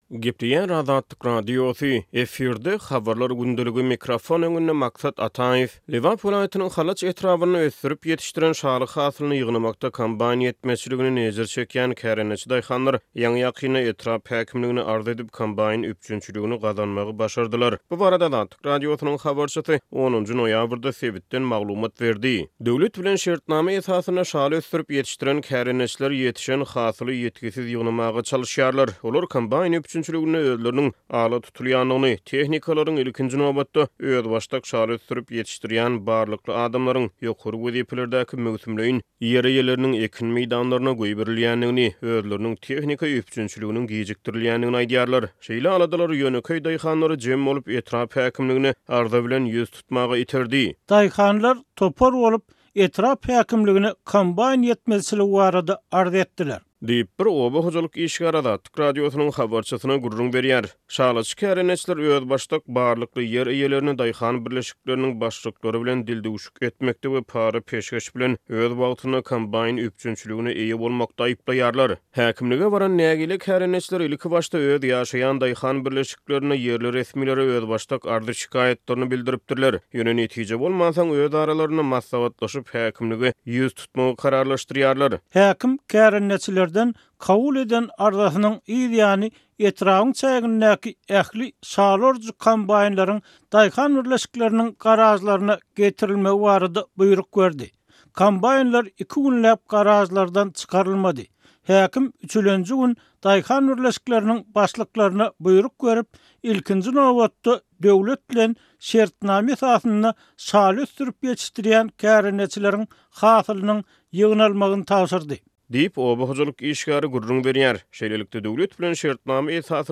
Lebap welaýatynyň Halaç etrabynda ösdürip ýetişdiren şaly hasylyny ýygnamakda kombaýn ýetmezçiliginden ejir çekýän kärendeçi daýhanlar ýaňy-ýakynda etrap häkimligine arz edip, kombaýn üpjünçiligini gazanmagy başardylar. Bu barada Azatlyk Radiosynyň habarçysy 10-njy noýabrda sebitden maglumat berdi.